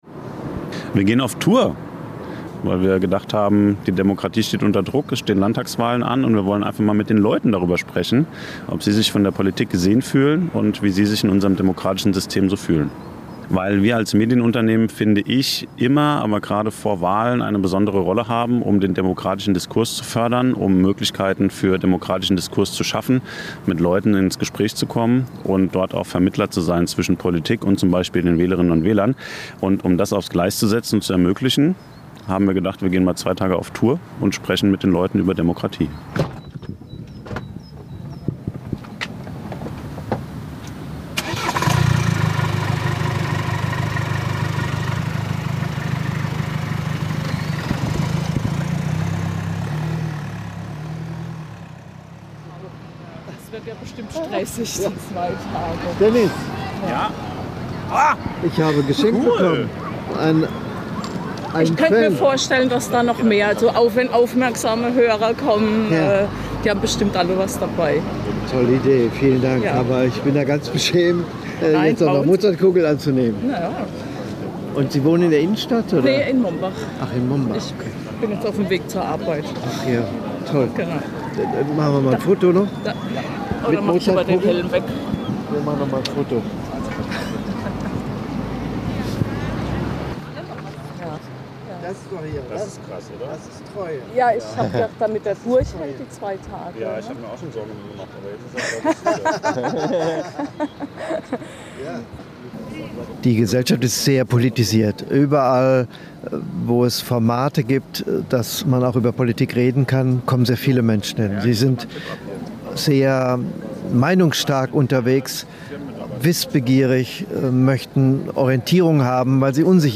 In einer reportagehaften Collage begleiten wir die beiden Hosts an ihren Marktstand, hören ihnen bei Gesprächen mit Passantinnen und Passanten zu und erleben, wie aus spontanen Begegnungen politische Gespräche entstehen. Zwischen persönlichen Anekdoten und nachdenklichen Momenten entsteht ein lebendiges Bild der Menschen hinter dem Podcast – und eine kleine Momentaufnahme davon, wie Demokratie im Alltag wirkt, wenn man ihr ganz nah kommt.